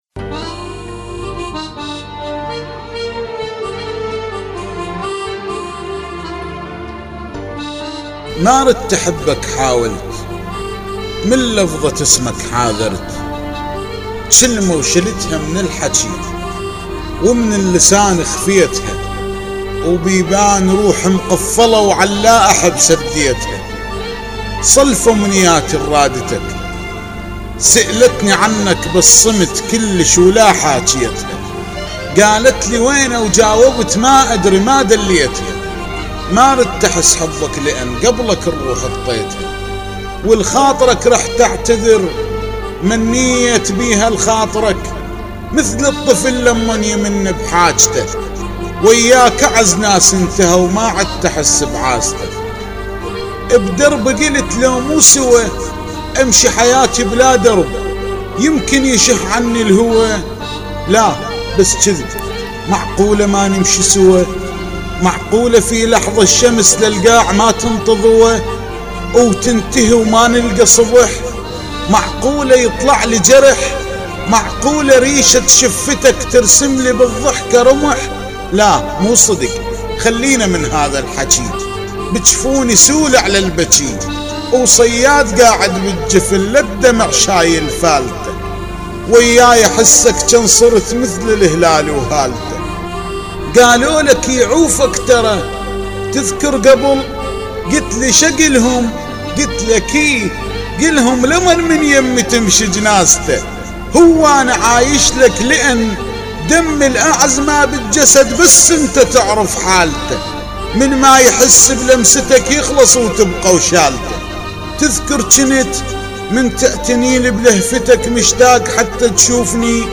للاستماع الى القصيده